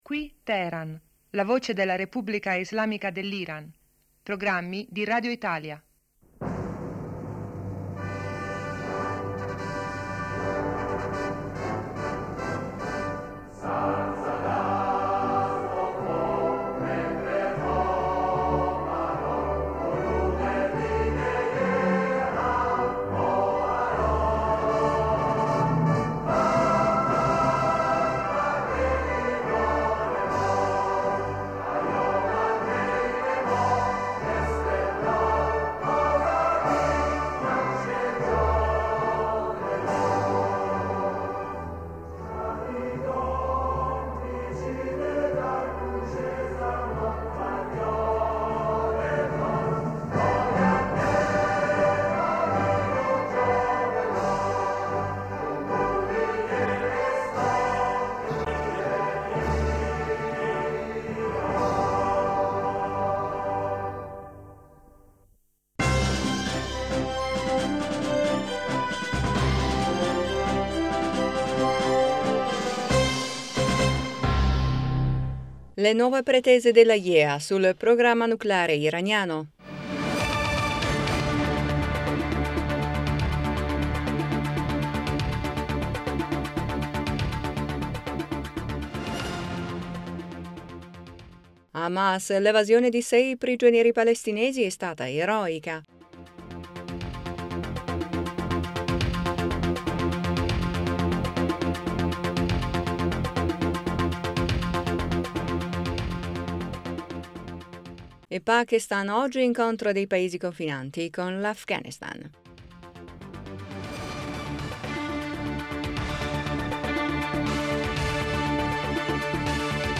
Ecco i titoli più importanti del nostro radiogiornale:1-Pakistan, oggi incontro dei paesi confinanti con Afghanistan,2-Le nuove pretese dell’Aiea sul progr...